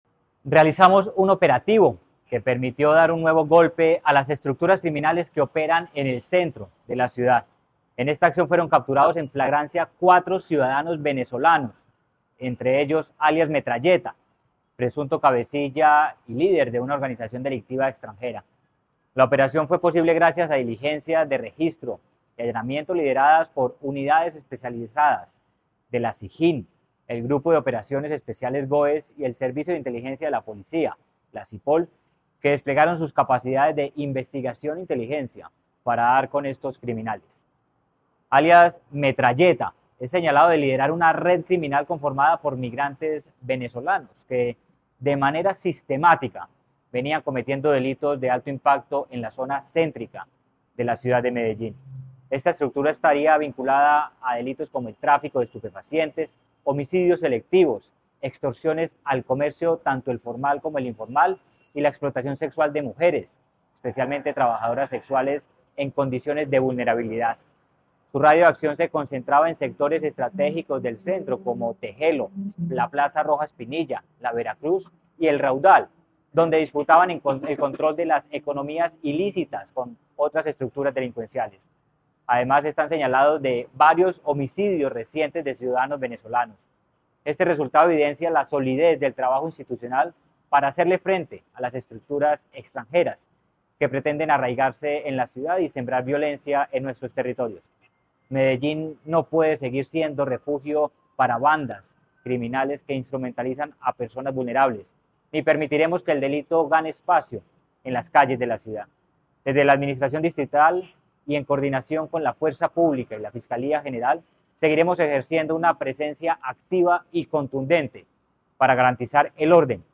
Declaraciones secretario de Seguridad y Convivencia Ciudadana, Manuel Villa Mejía
Declaraciones-secretario-de-Seguridad-y-Convivencia-Ciudadana-Manuel-Villa-Mejia.mp3